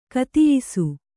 ♪ katiyisu